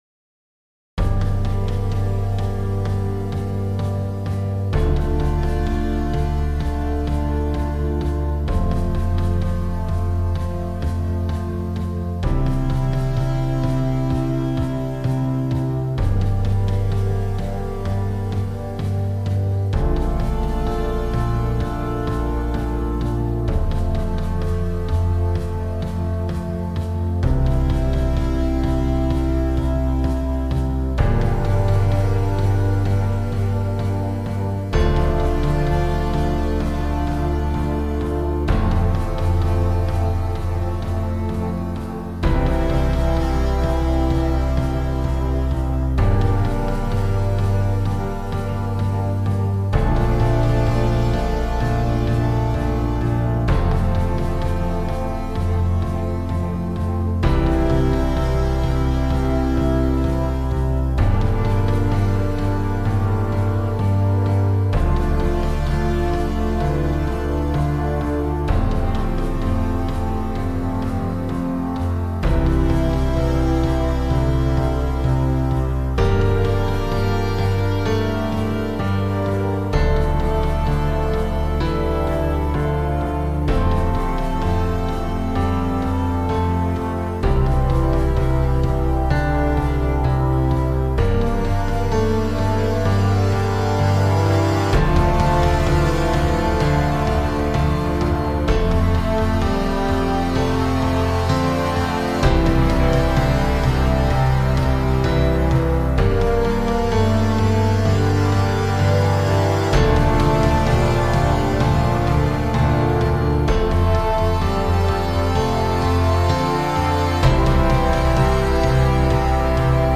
for full concert band
Drum Set
Concert Bass Drum
Piano